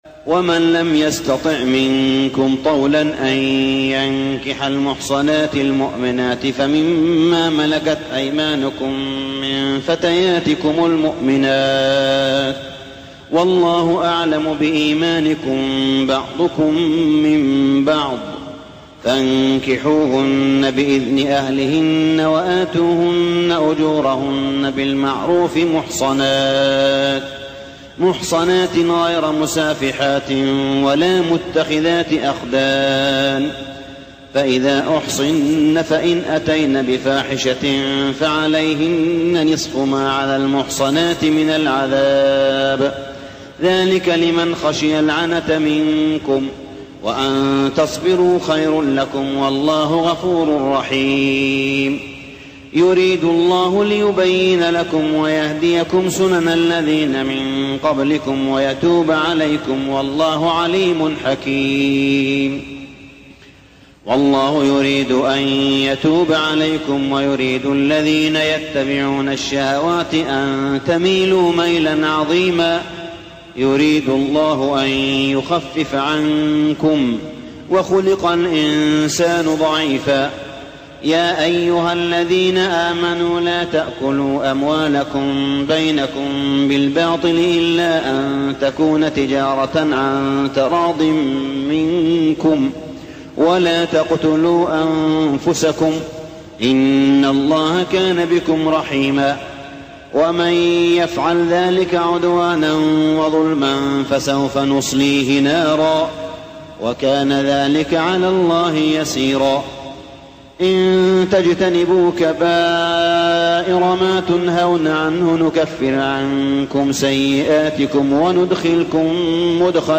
صلاة التراويح ليلة 5-9-1411هـ سورة النساء 25-87 | Tarawih Prayer night 5-9-1411AH Surah An-Nisa > تراويح الحرم المكي عام 1411 🕋 > التراويح - تلاوات الحرمين